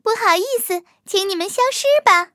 卡尔臼炮夜战攻击语音.OGG